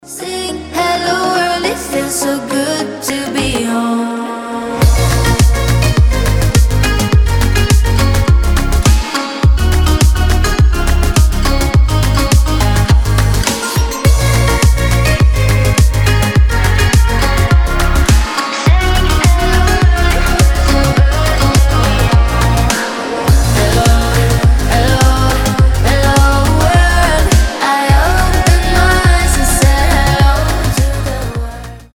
• Качество: 320, Stereo
позитивные
мелодичные
Midtempo
приятные
добрые